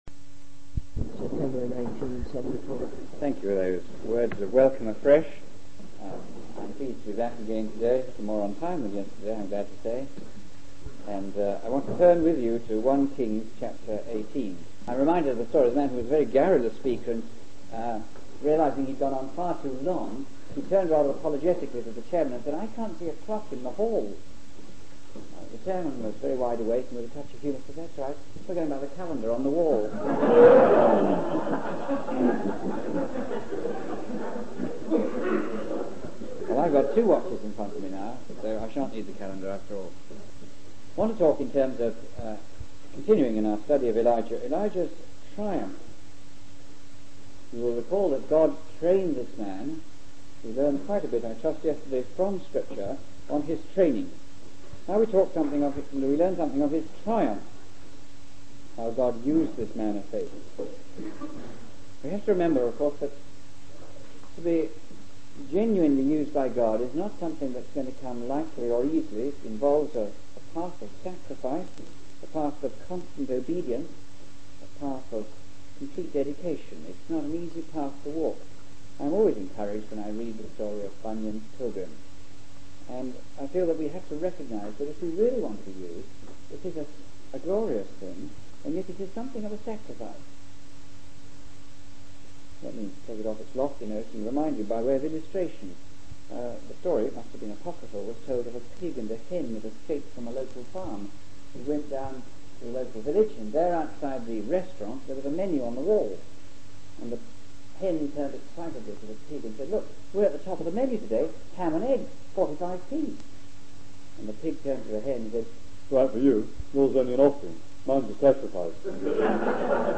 In this sermon, the speaker describes a scene on Mount Carmel where the people gathered to witness a spectacle between the prophets of Baal and the prophet Elijah. The speaker emphasizes the importance of choosing to follow the Lord rather than being indecisive.